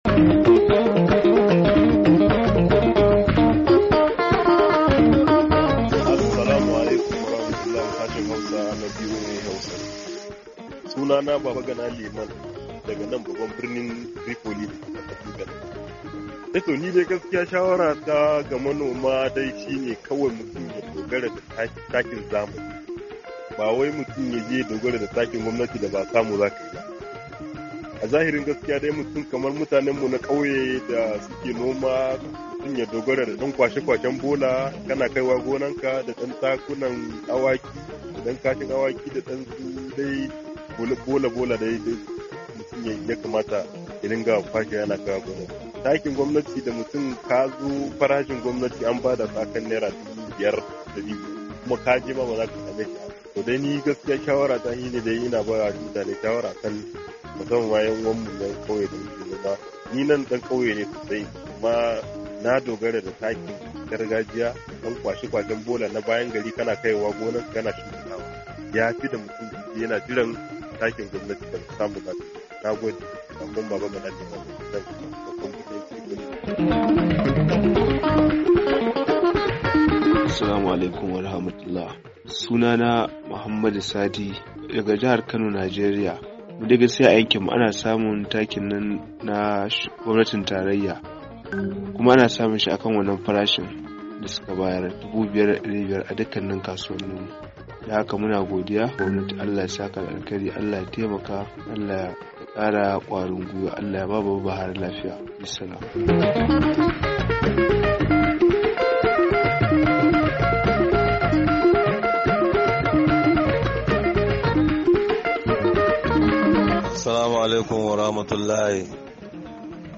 Sakonnin Ra'ayoyin Masu Sauraro Ta Kafar WhatsApp